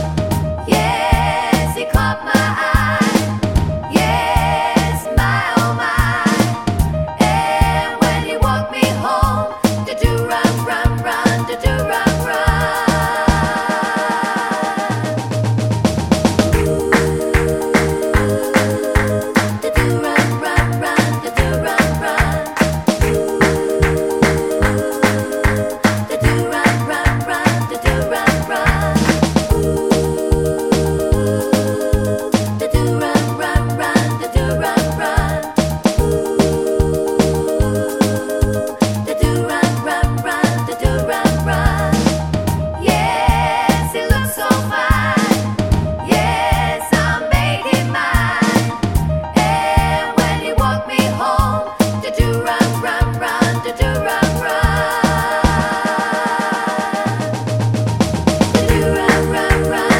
no Backing Vocals Soul / Motown 2:15 Buy £1.50